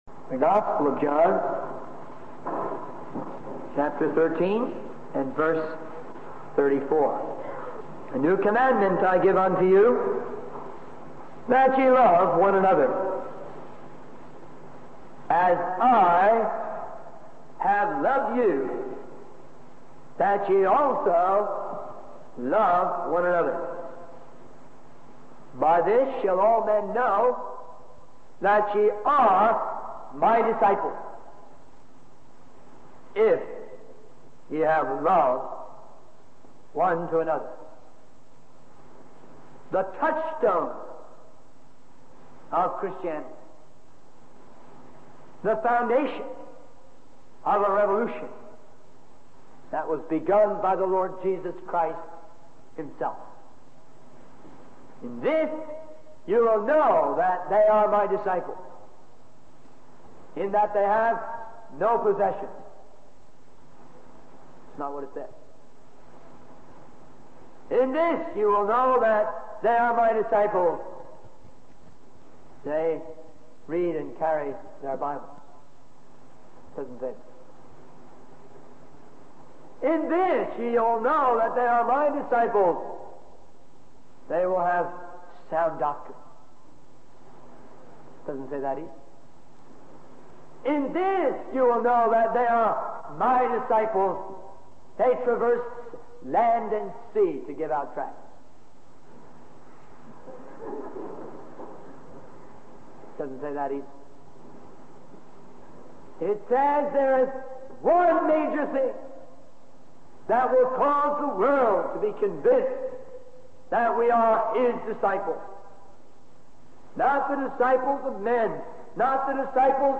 In this sermon, the preacher emphasizes the importance of standing for righteousness and not conforming to the ways of the world.